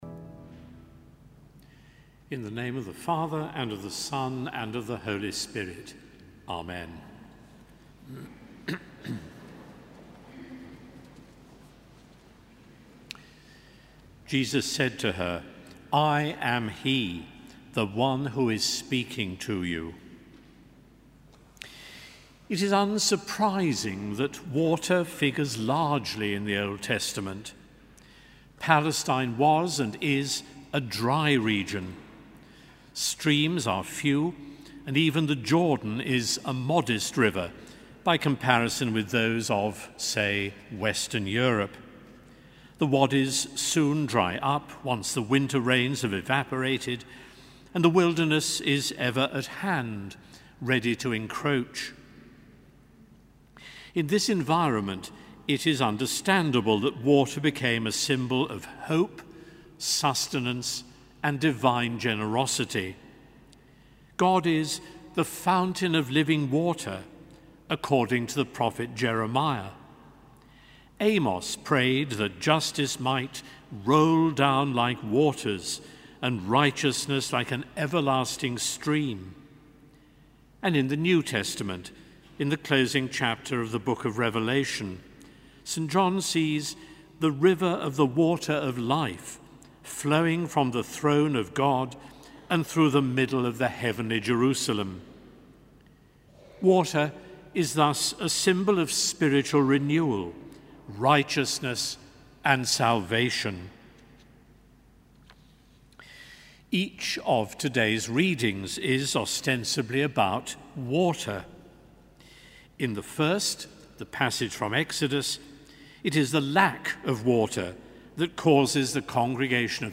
Sermon: Third Sunday of Lent 2014